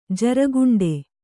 ♪ jaraguṇḍe